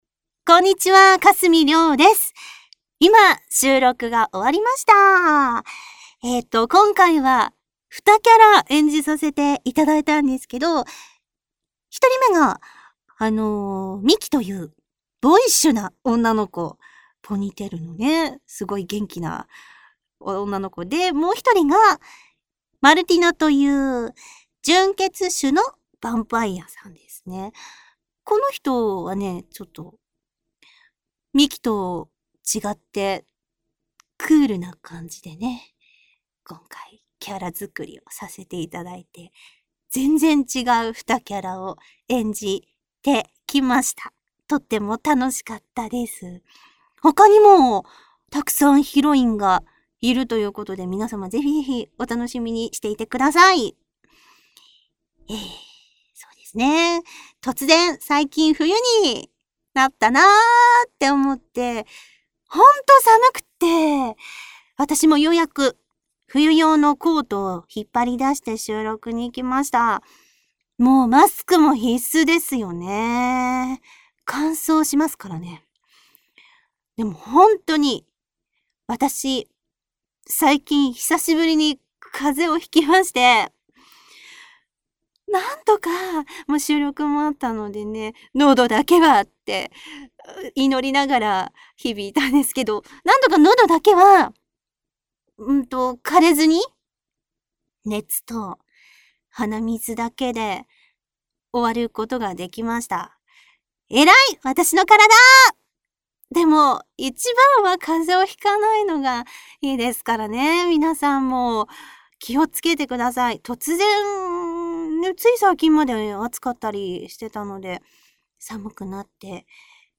さてさて、声優さんからコメントを頂いております。